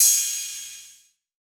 Crashes & Cymbals
Crash (1).wav